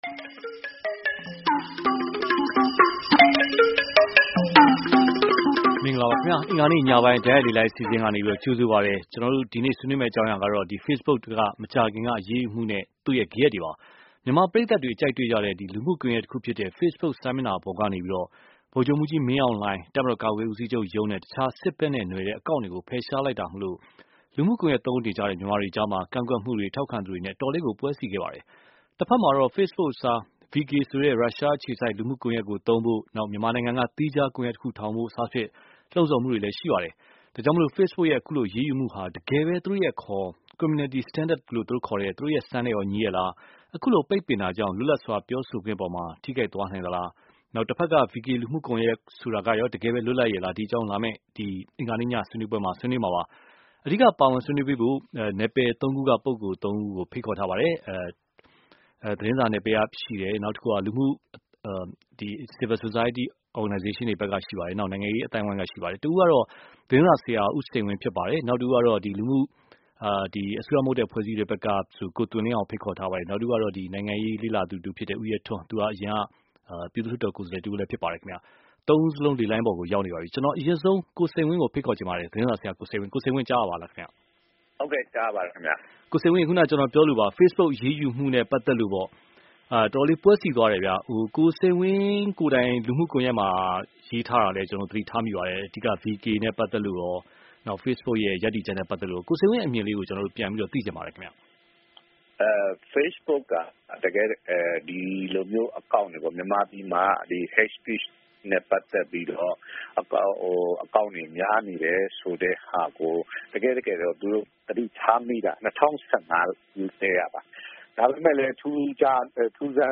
ဖေ့ဘုတ်အရေးယူမှုနဲ့ ဂယက် (တိုက်ရိုက်လေလှိုင်း)